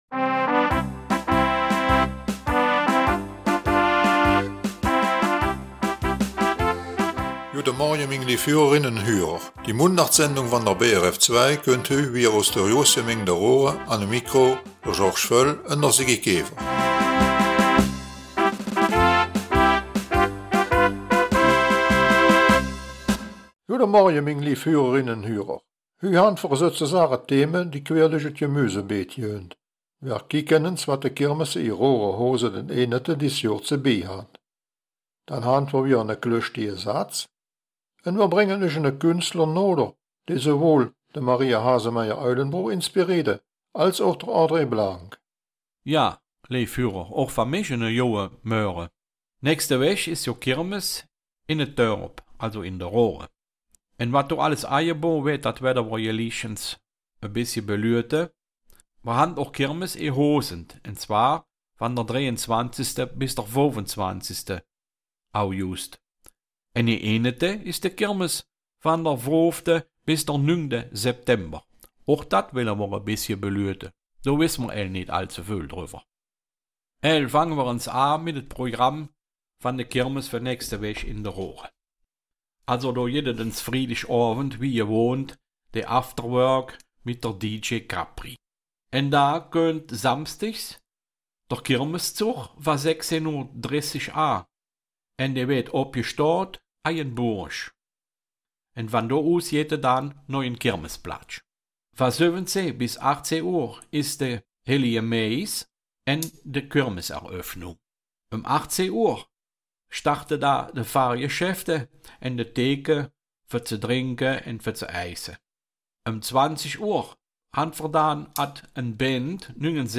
Raerener Mundart - 10. August
Die Mundartsendung vom 10.08.2024 aus Raeren bringt folgende Themen: